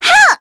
Cassandra-Vox_Damage_02.wav